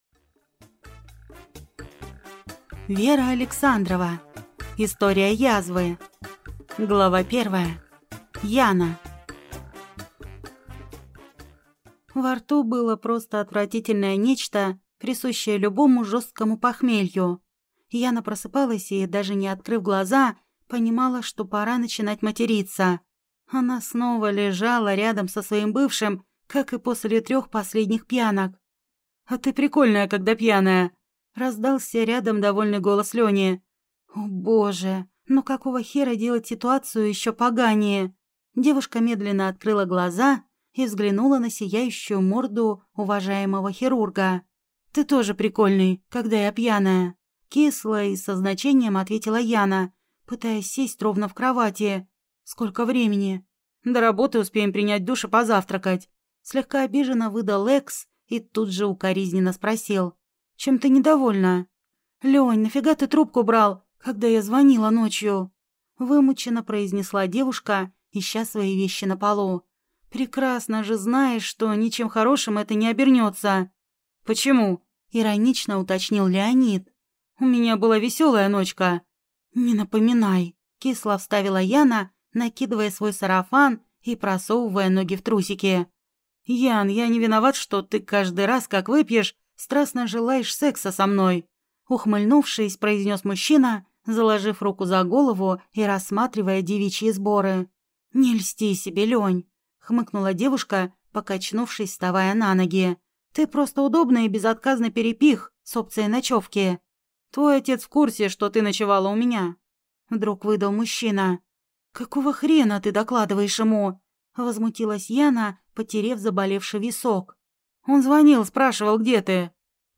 Aудиокнига История Язвы